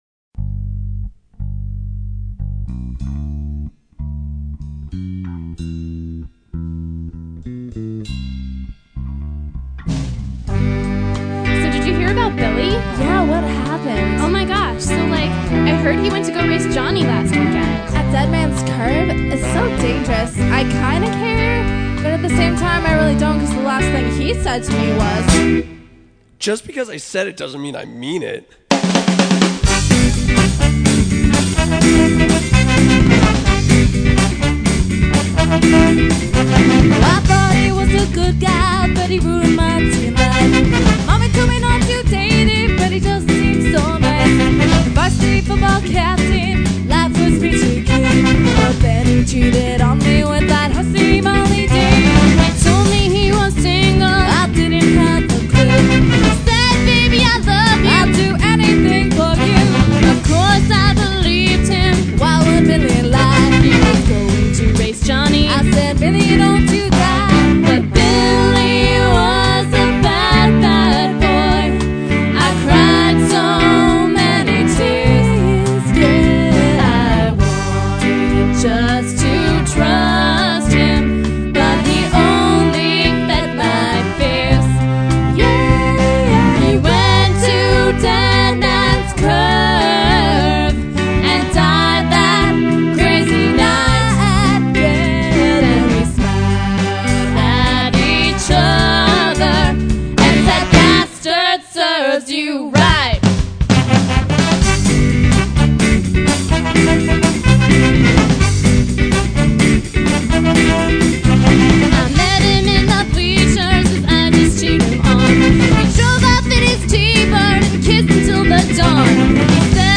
Somewhere between ska, rock and pop, the album features covers of songs by Save Ferris, No Doubt, and Reel Big Fish.  Three original songs round out the CD with lots of energy and fun.
Recorded by Afiinity Productions (Mobile Unit).